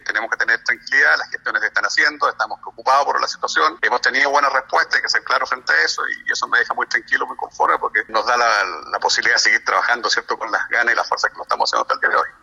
En conversación con Radio Sago, el alcalde de Osorno, Jaime Bertín, confirmó que habló con el Secretario de Estado, Enrique Paris, durante estos últimos días respecto al stock de vacunas Sinovac, ante lo cual se afirmó que este miércoles estarán disponibles y se podría retomar a la brevedad el proceso.